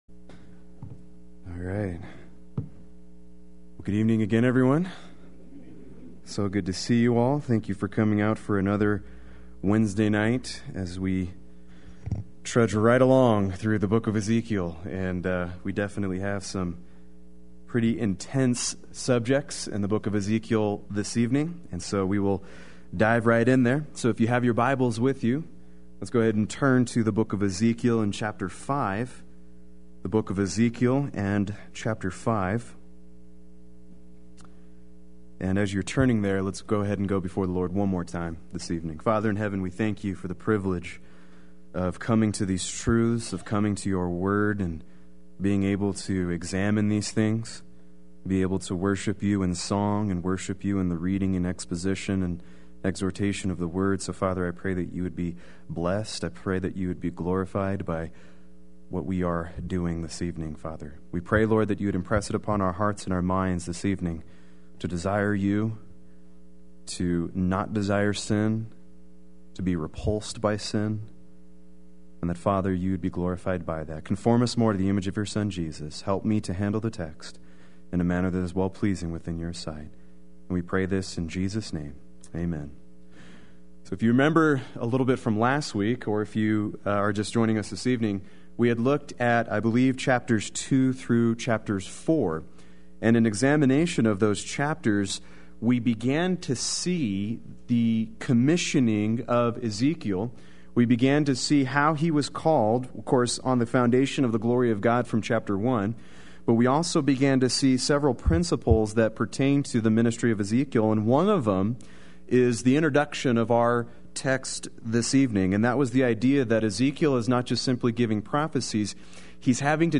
Play Sermon Get HCF Teaching Automatically.
Chapter 5-7:9 Wednesday Worship